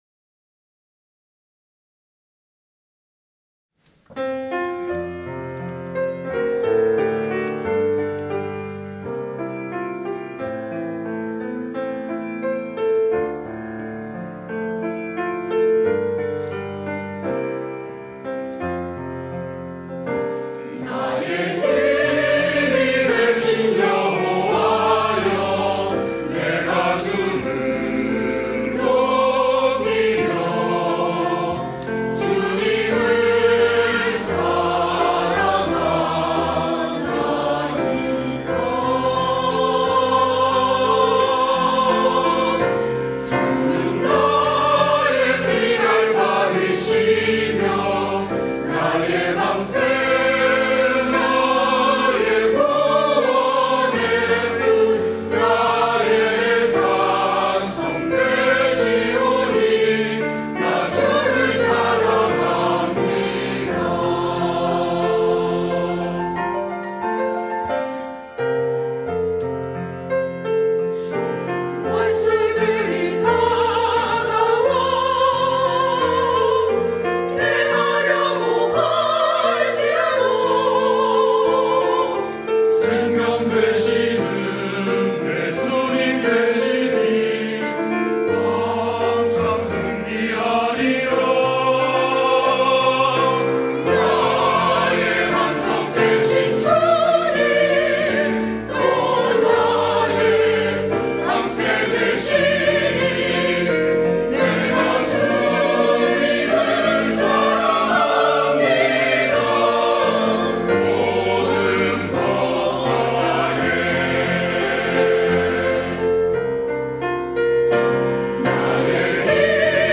임마누엘